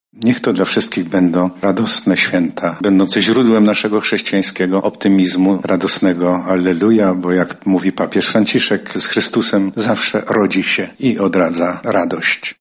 Podczas spotkania pracownicy Urzędu Marszałkowskiego Województwa Lubelskiego, a także zaproszeni Wojewoda Lubelski, prezydenci, czy rektorzy lubelskich uczelni, mieli okazję między innymi złożyć sobie życzenia świąteczne.
Wśród zaproszonych był również Arcybiskup Stanisław Budzik, Metropolita Lubelski. Także i on złożył życzenia zebranym: